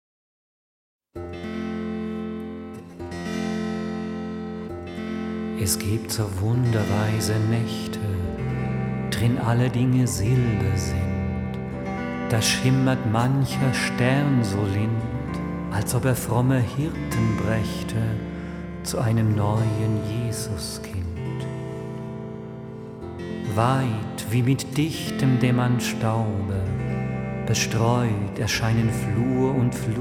Alte & neue europäische Weisen mit europäischen Instrumenten